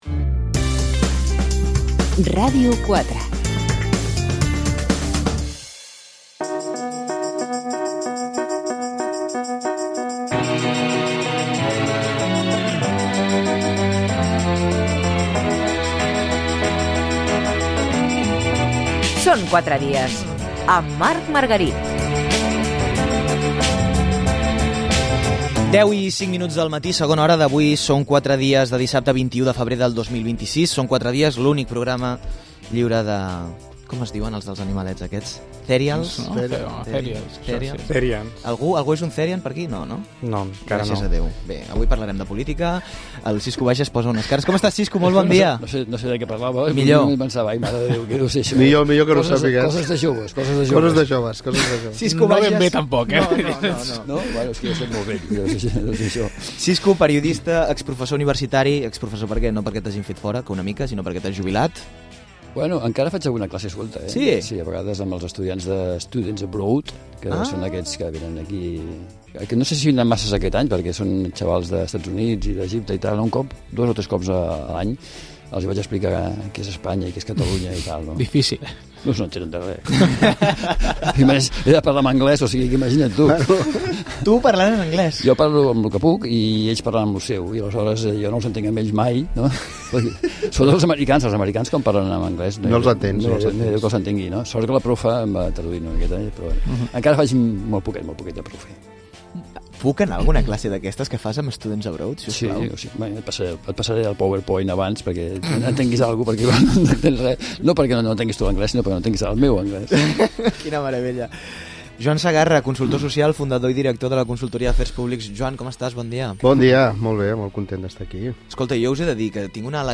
Tertúlia al Són 4 dies de Ràdio 4